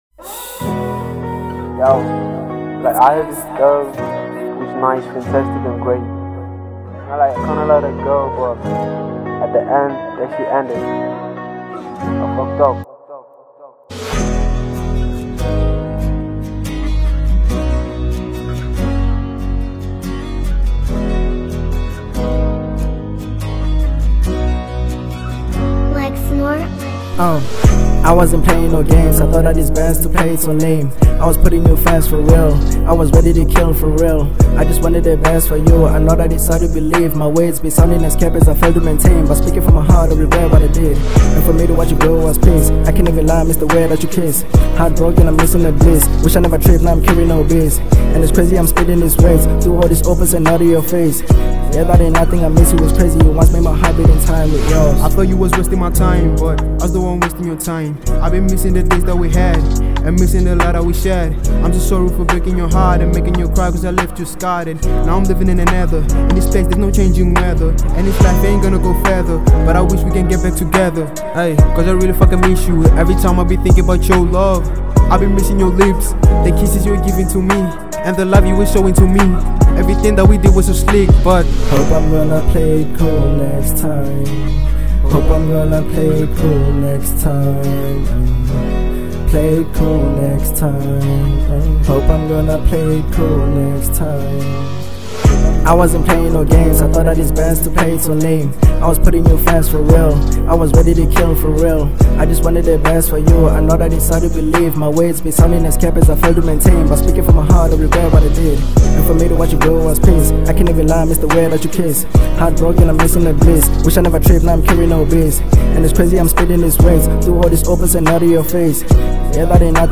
03:38 Genre : RnB Size